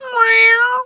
cat_2_meow_09.wav